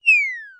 default_death1.mp3